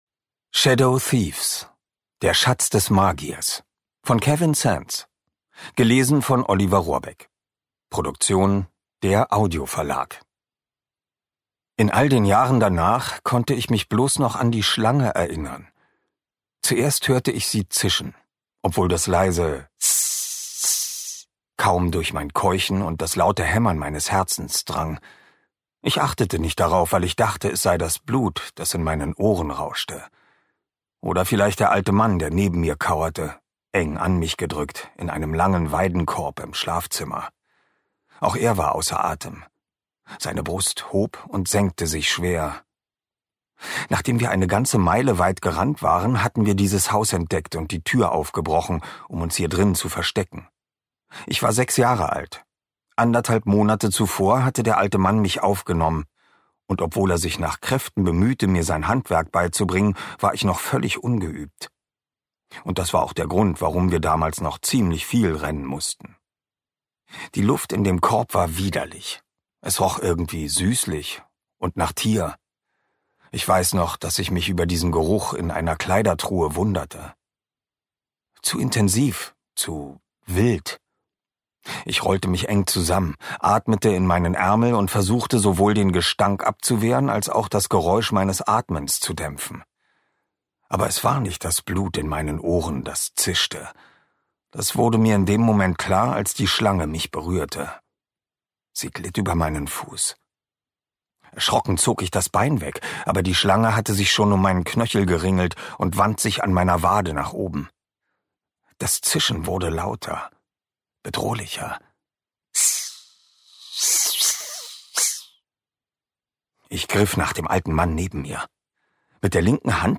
Ungekürzte Lesung mit Oliver Rohrbeck (2 mp3-CDs)
Oliver Rohrbeck (Sprecher)